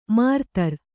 ♪ mārtar